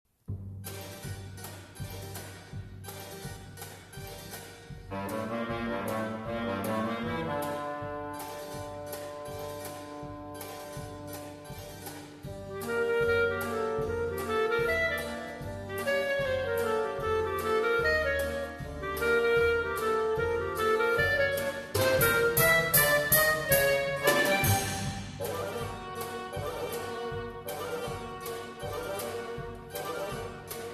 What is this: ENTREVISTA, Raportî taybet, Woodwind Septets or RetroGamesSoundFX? Woodwind Septets